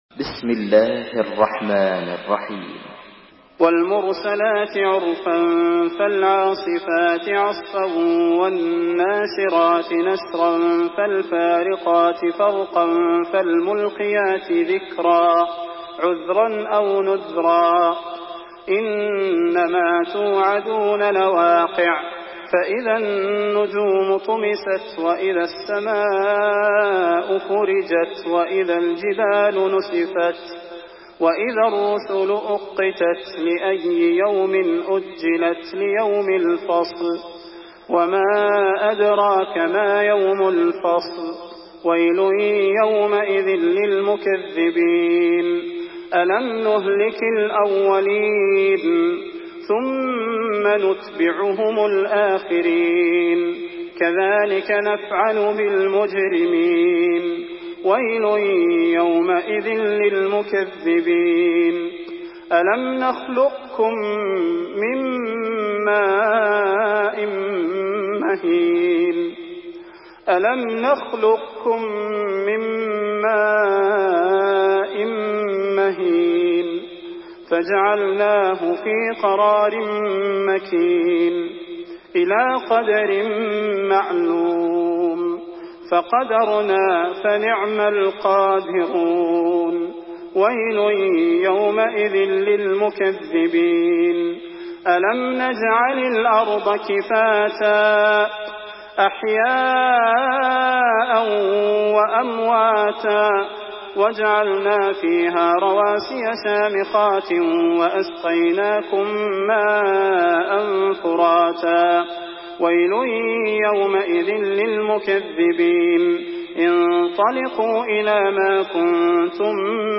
Surah Mürselat MP3 by Salah Al Budair in Hafs An Asim narration.
Murattal Hafs An Asim